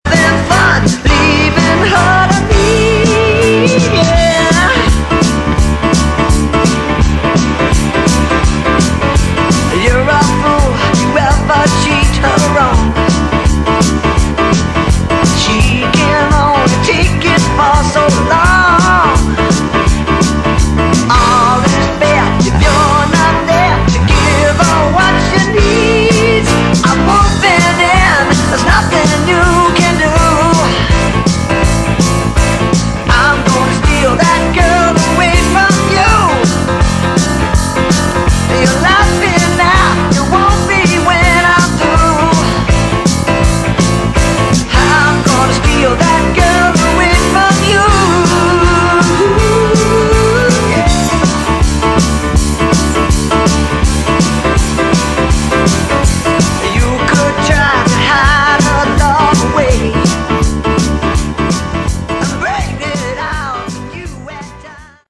Category: Melodic Rock
lead vocals
keyboards
guitars
bass
drums